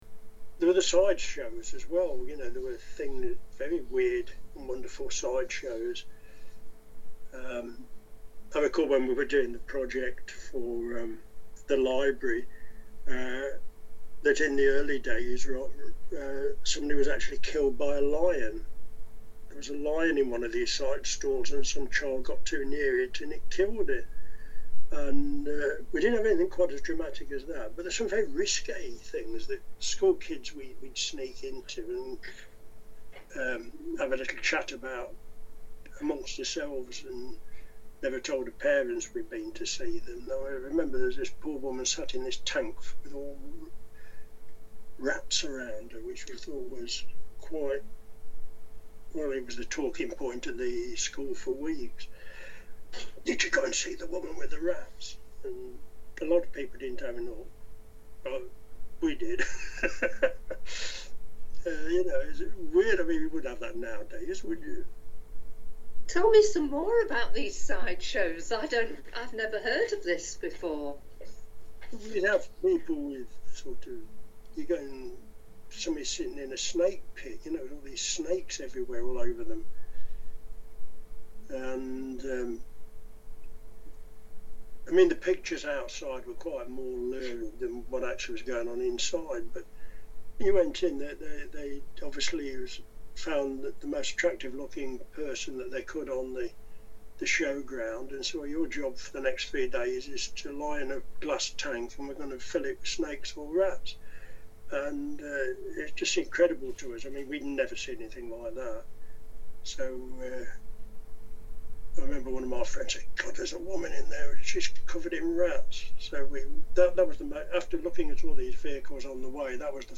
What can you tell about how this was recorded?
Listen below to audio clips taken from interviews conducted by our volunteers with the community reflecting on 800 years of Loughborough’s fair.